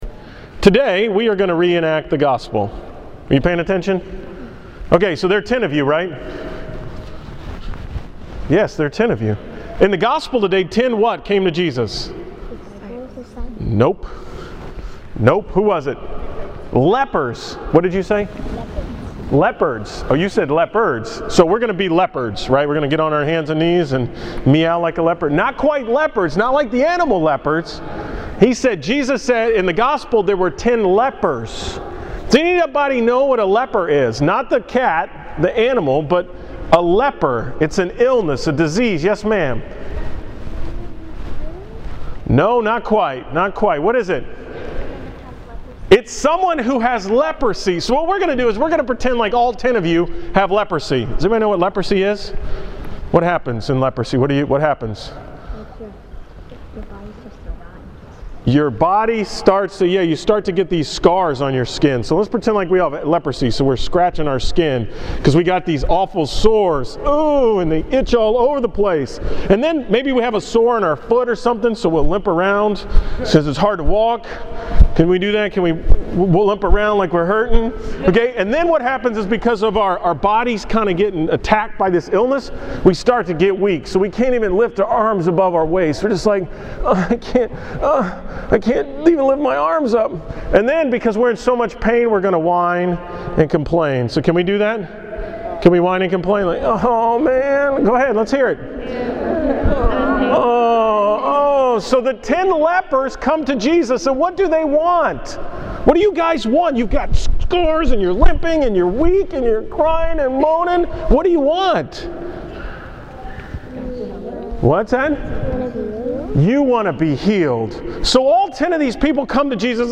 From the School Mass before Thanksgiving break